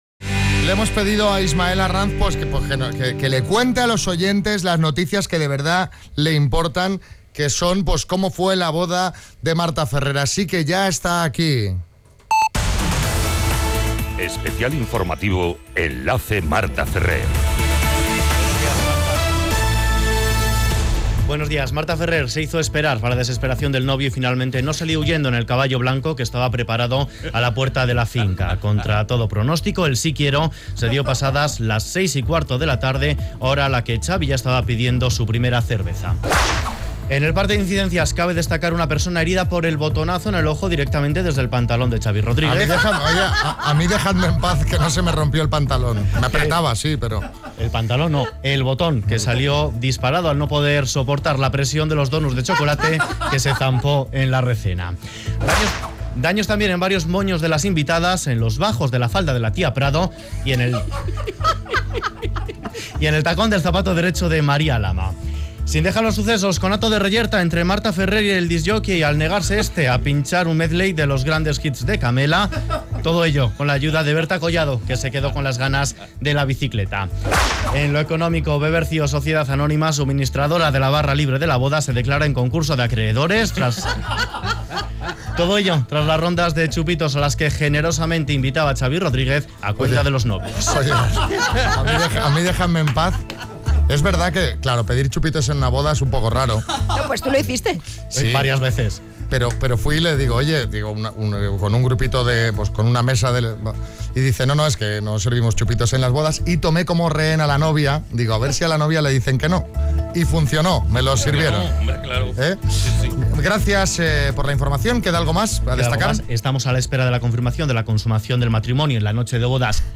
Boletín especial